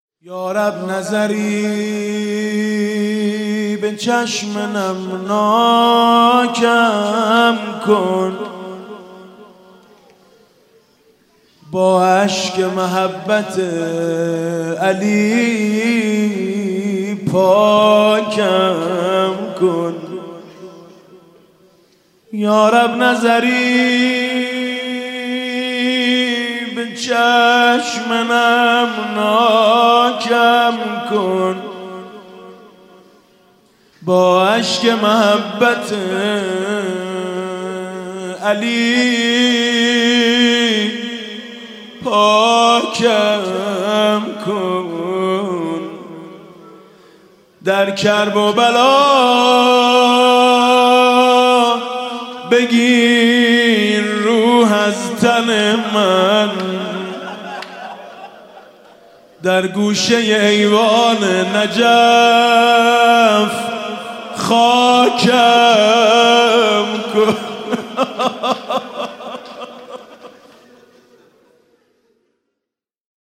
شب چهارم رمضان 96 - هیئت شهدای گمنام - مدح - گوشه ایوان نجف